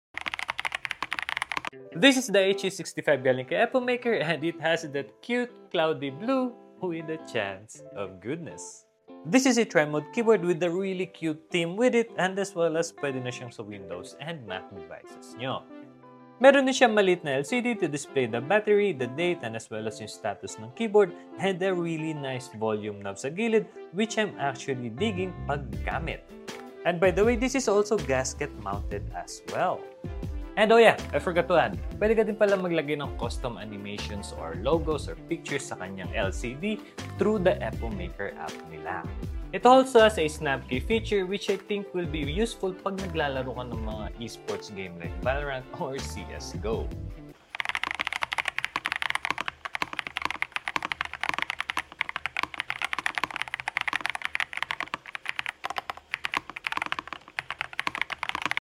What do you think about the flamingo switches sound?